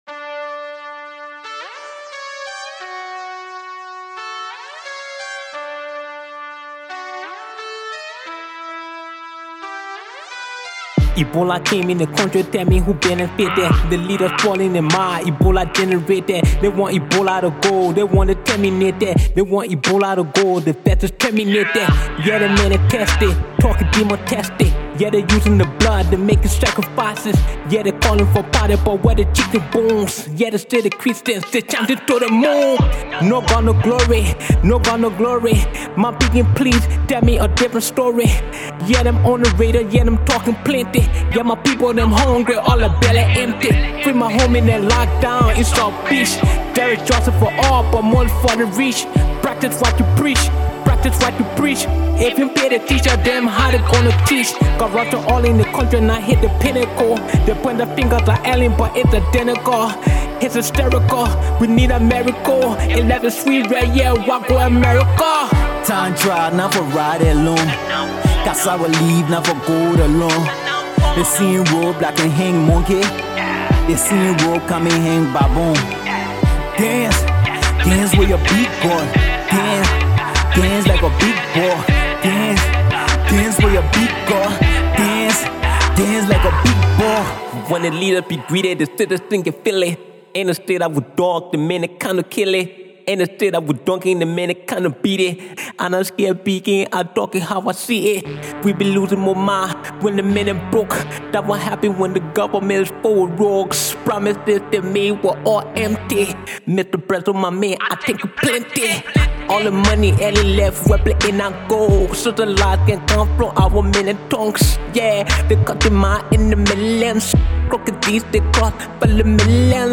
Hip-Co